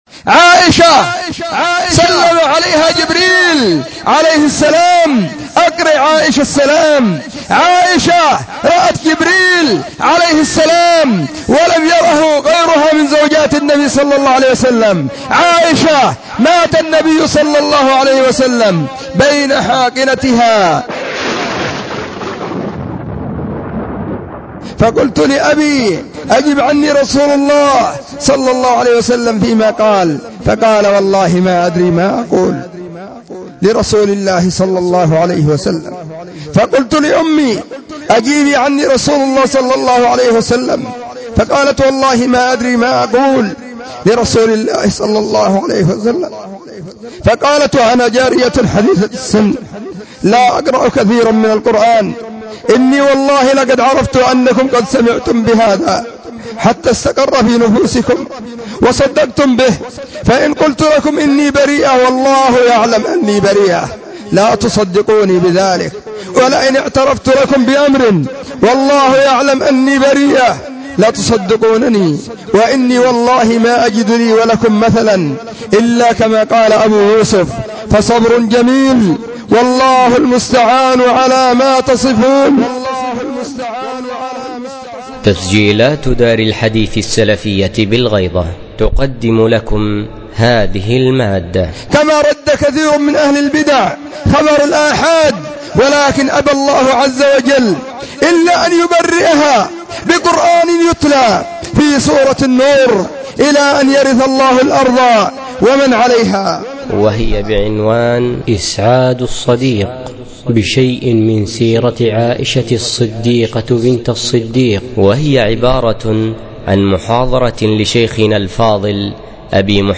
📢 مسجد الصحابة – بالغيضة – المهرة، اليمن حرسها •اللّـہ.